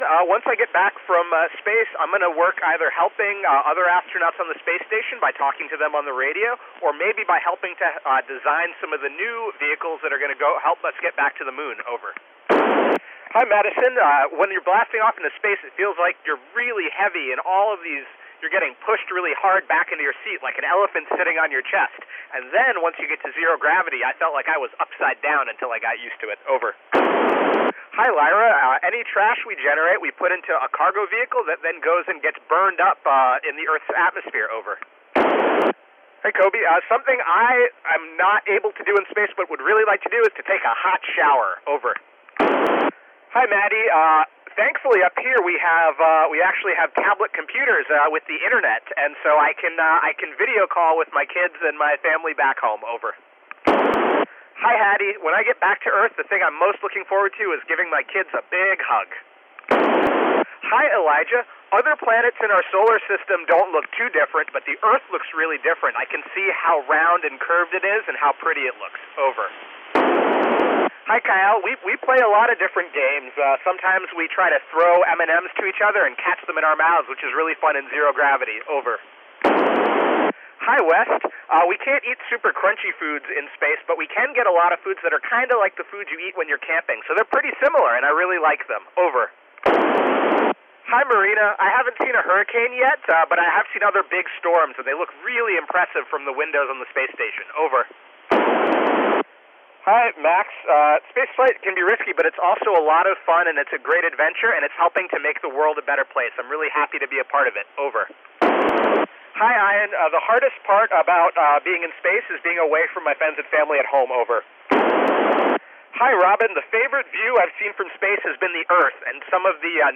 This is a recording of the downlink signal of the ISS during the school contact with Conn Magnet Elementary School in Raleigh, North Carolina. Because I'm way too far away from the school (North East Pennsylvania) I cannot hear the students asking the questions.